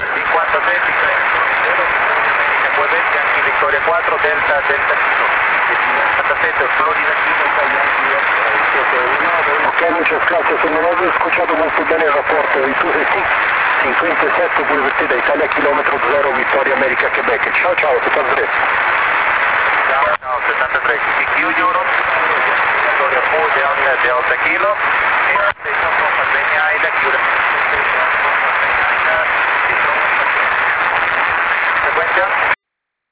Hear His Signal in Rome!